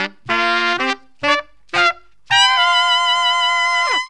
HORN RIFF 6.wav